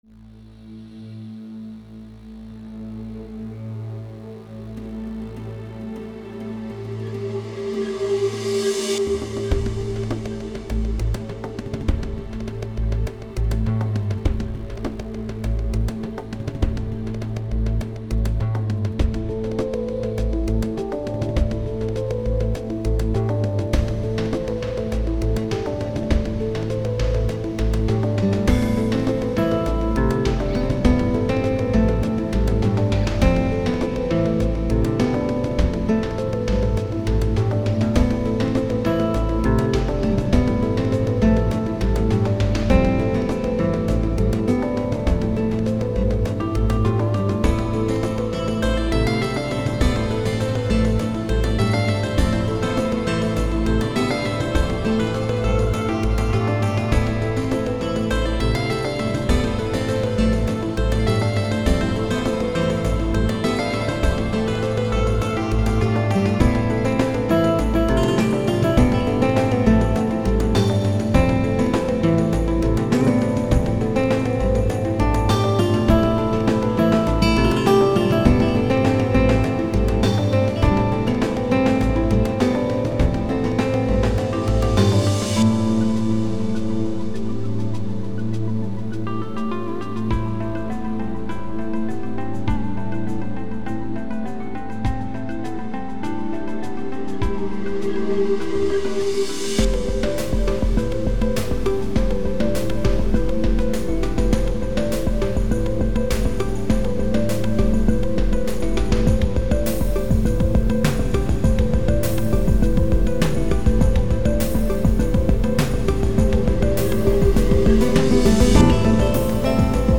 These are links to some sample pieces that I have composed using soundfonts and samples.
Virtue - I cannot take credit for originality here. This piece is directly based off of another electronica/guitar piece that I don't know the name of.